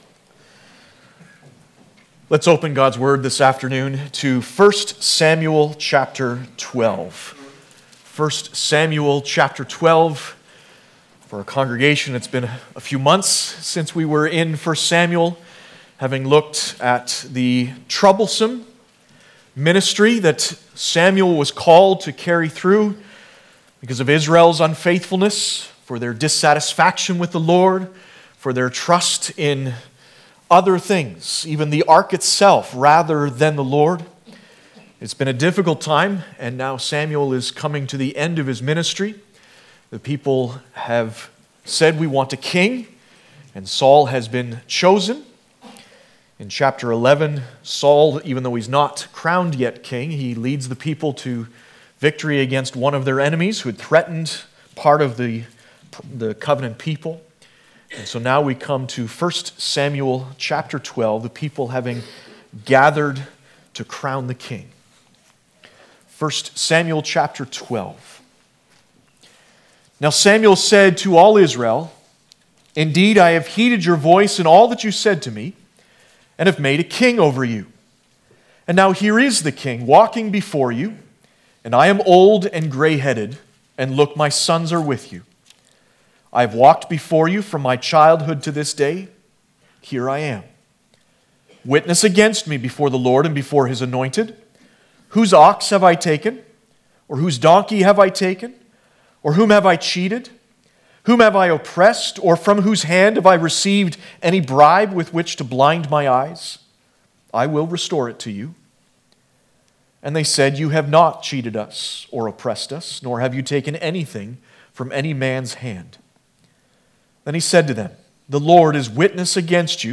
Passage: 1 Samuel 12 Service Type: Sunday Afternoon « The Song of the Vineyard